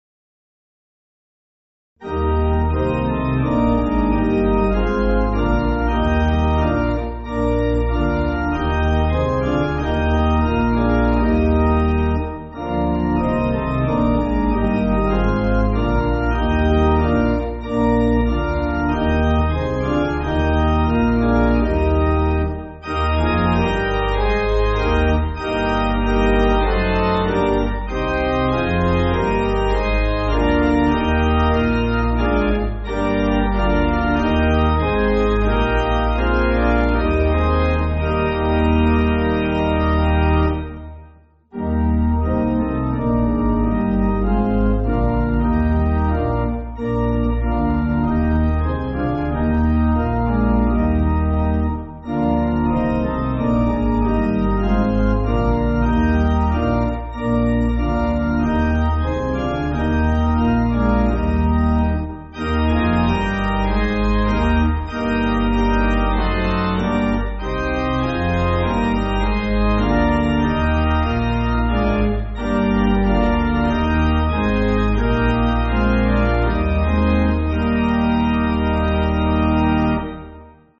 (CM)   3/Eb